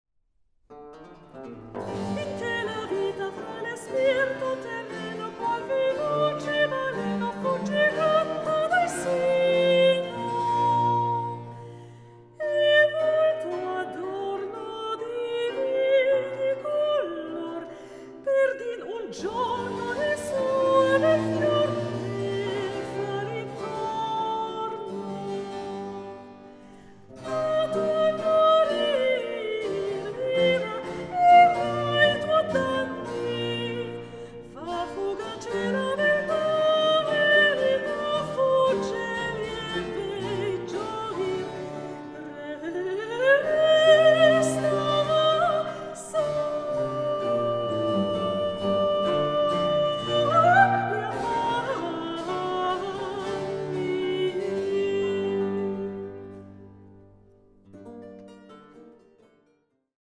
Baroque works on the theme of love in human and divine form
Soprano
The finely balanced ensemble and the agile, expressive voice